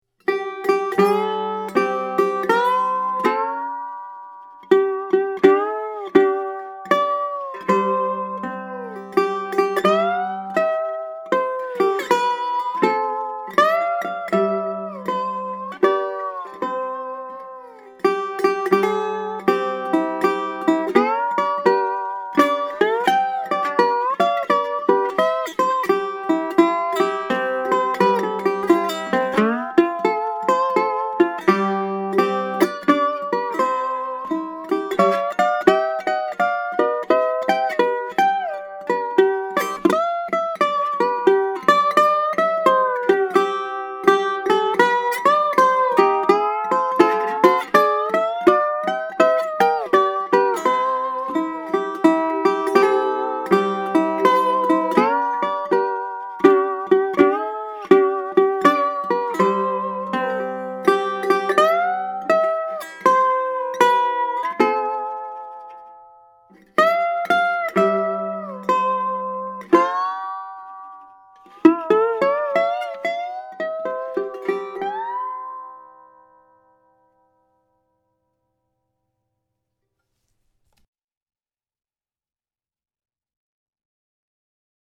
Happy Birthday on the Lap Steel!
Played on his Mya-Moe Black & White Ebony Lap Steel Ukulele, of course.